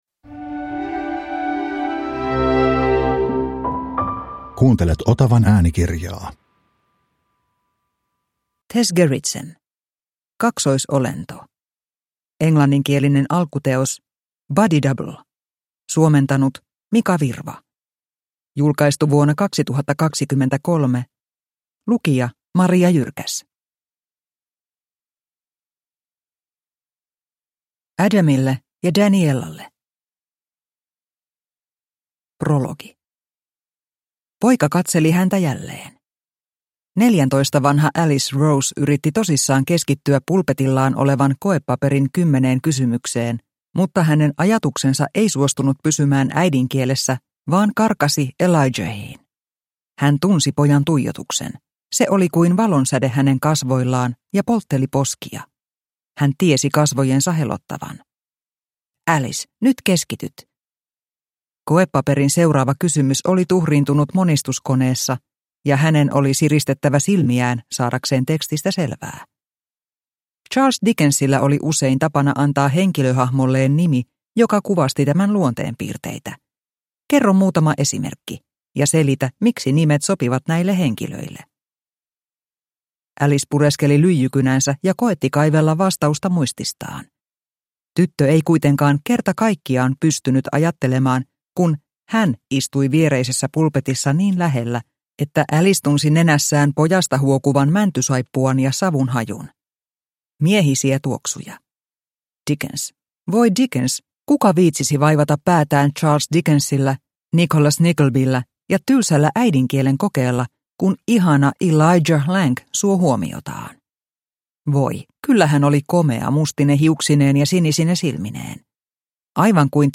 Kaksoisolento – Ljudbok – Laddas ner
Uppläsare: